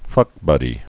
(fŭkbŭdē)